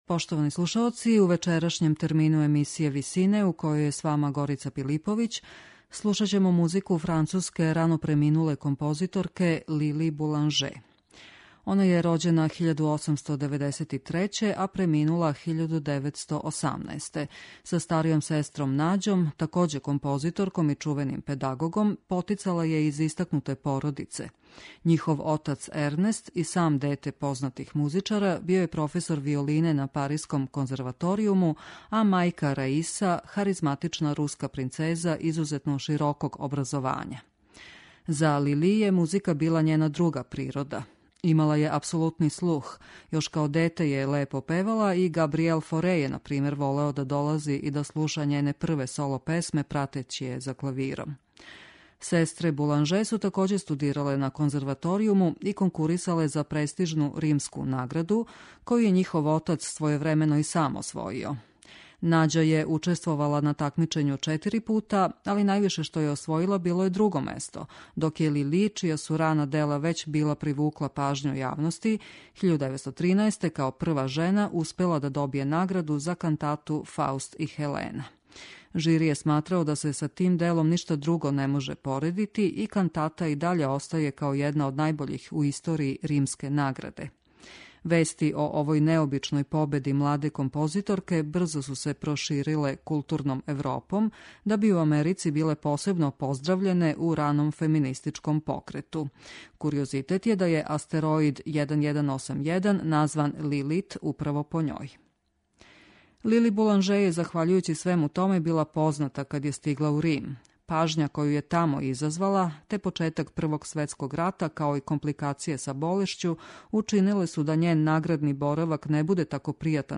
Међу 20-ак композиција које су остале у легату француске ауторке Лили Буланже истиче се неколико соло-песама, лирских сцена, клавирских комада и три псалма за различите ансамбле.
медитативне и духовне композиције